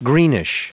Prononciation du mot greenish en anglais (fichier audio)
Prononciation du mot : greenish